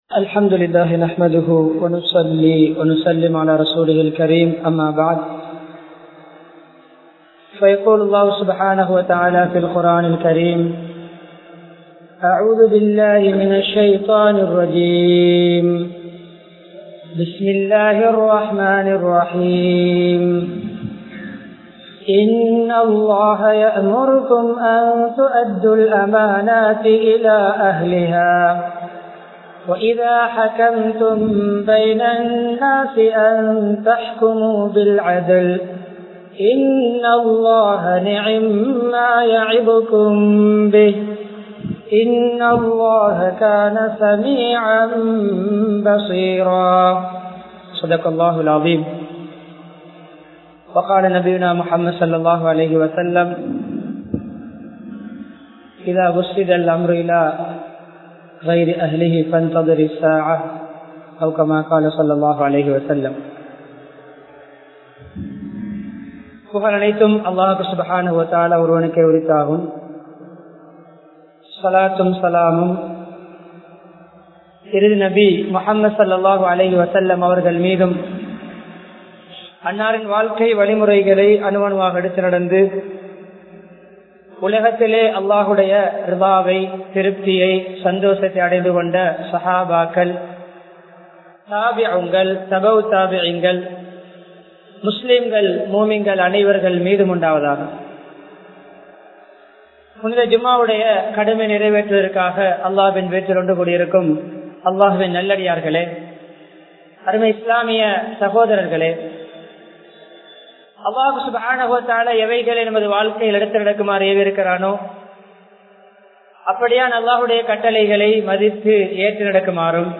Masjidh Samuthaayaththin Adippadai (மஸ்ஜித் சமூதாயத்தின் அடிப்படை) | Audio Bayans | All Ceylon Muslim Youth Community | Addalaichenai